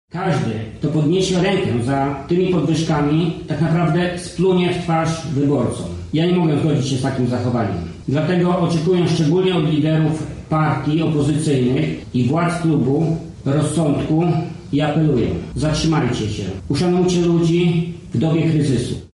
Jak nazwać dawanie sobie gigantycznych podwyżek w czasach kiedy polska gospodarka wchodzi w potężny kryzys?– mówi senator Jacek Bury: